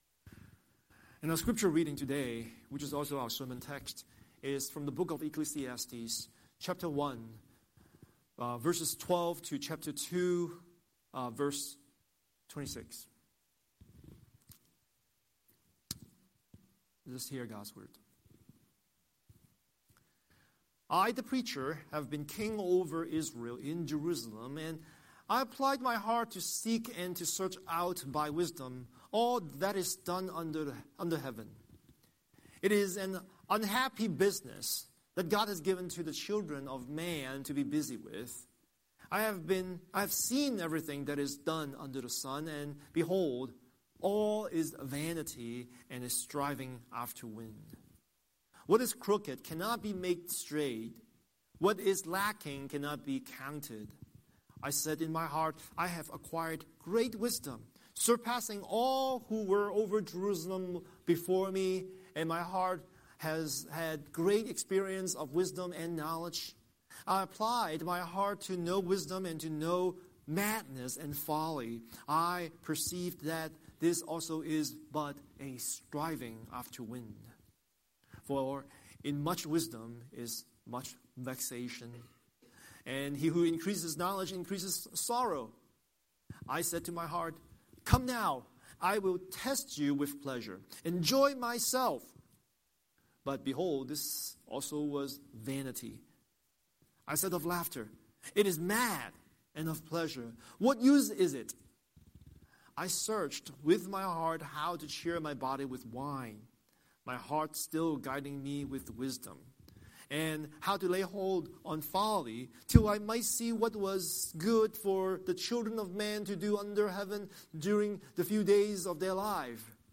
Scripture: Ecclesiastes 1:12–2:26 Series: Sunday Sermon